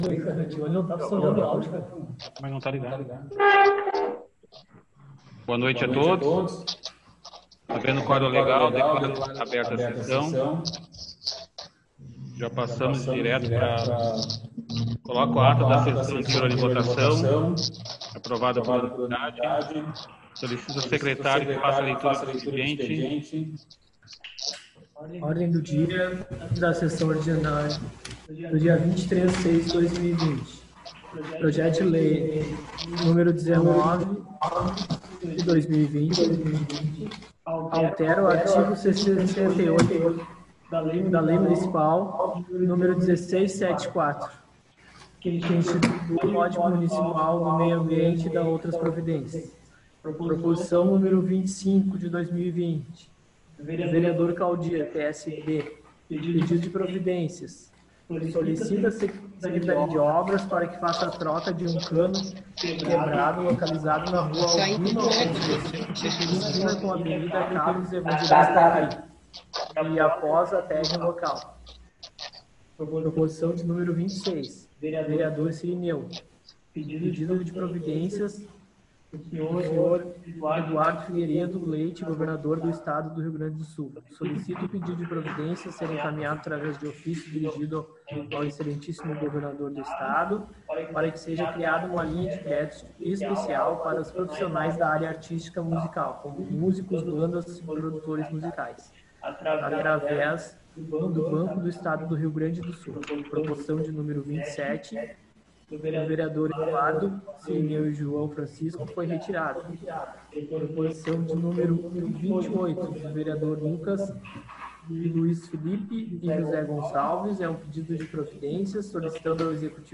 1ª Sessão Online.